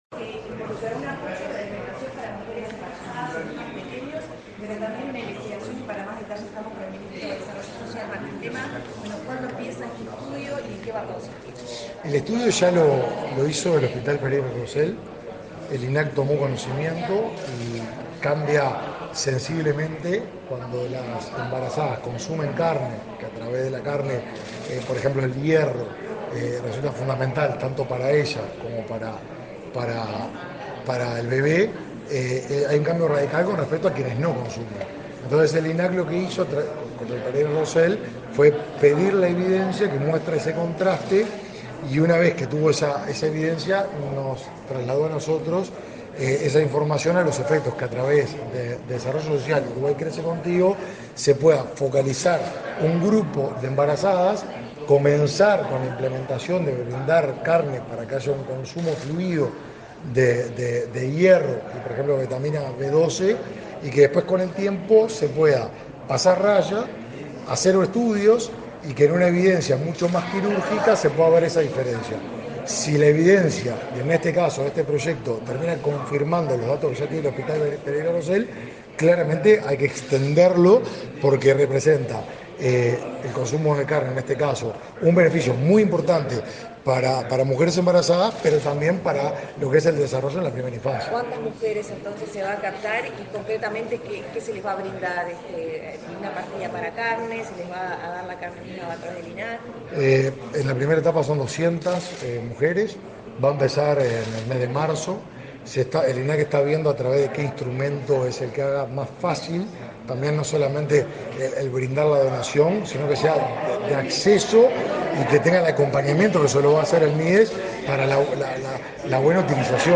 Declaraciones a la prensa del ministro del Mides, Martín Lema
Declaraciones a la prensa del ministro del Mides, Martín Lema 06/02/2024 Compartir Facebook X Copiar enlace WhatsApp LinkedIn El Ministerio de Desarrollo Social (Mides) y el Instituto Nacional de Carne (INAC) firmaron un convenio, este 6 de febrero, para implementar una estrategia de intervención de apoyo alimentario de carne a embarazadas participantes de Uruguay Crece Contigo. Tras el evento, el ministro Martín Lema realizó declaraciones a la prensa.